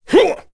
Kaulah-Vox_Attack1.wav